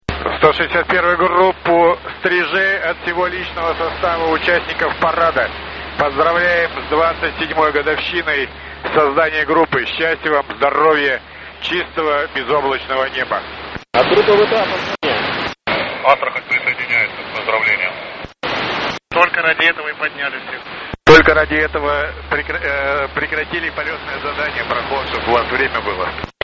Начало » Записи » Записи радиопереговоров - авиация
Поздравление АГВП Стрижи с 27-летием от летчиков парадного строя во время тренировки 06 мая 2018 года